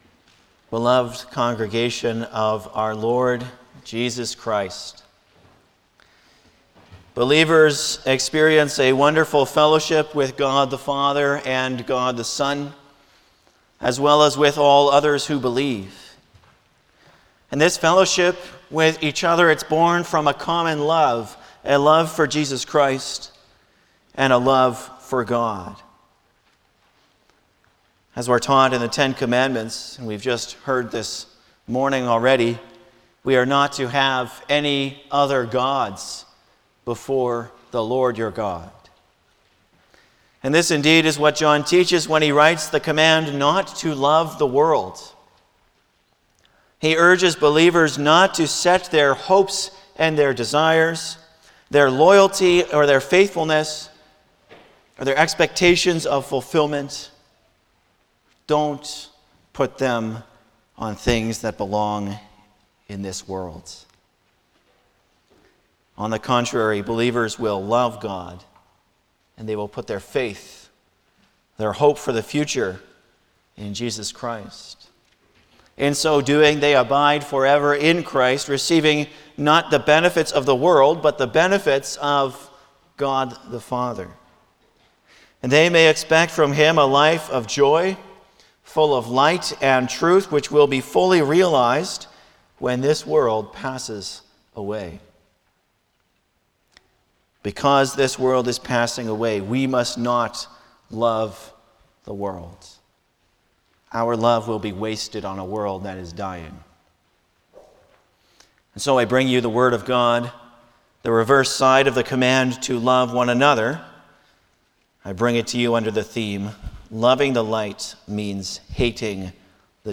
Passage: 1 John 2:15-17 Service Type: Sunday morning
09-Sermon.mp3